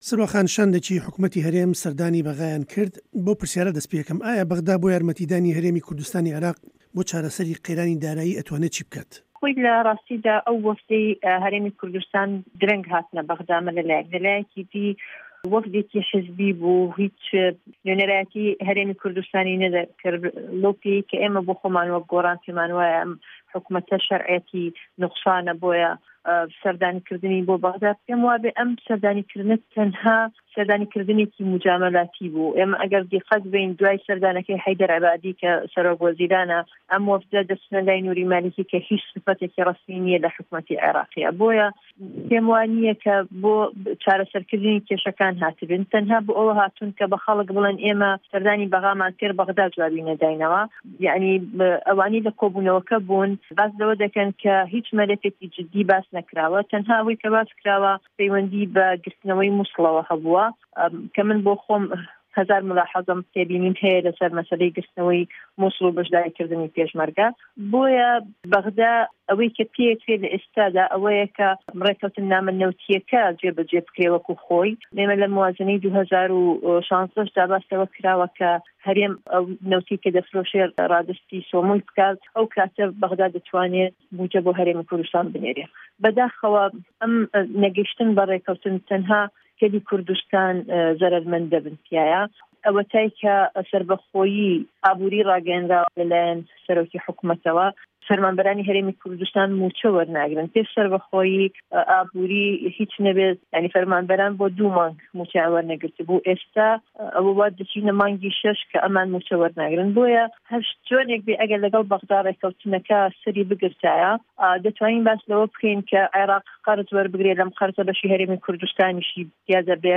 گفتووگۆ له‌گه‌ڵ سروه‌ عه‌بدولواحید